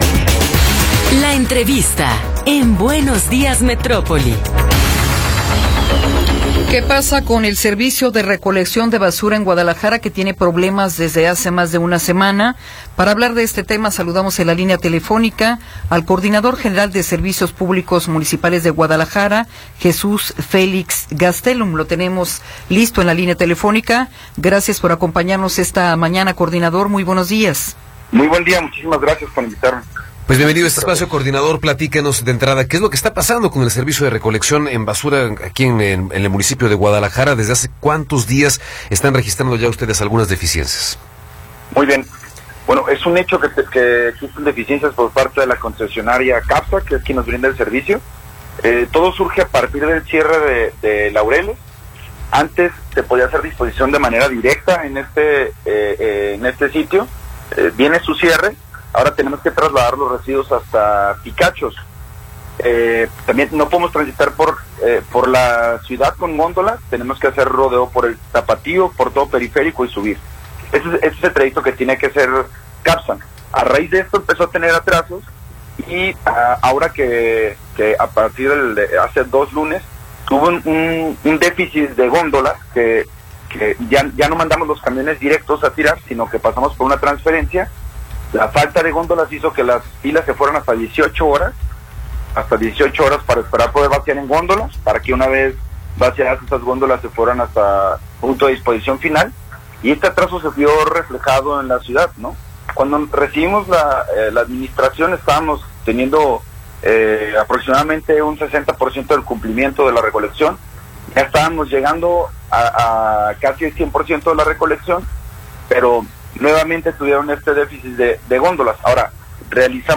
Entrevista con Jesús Félix Gastelum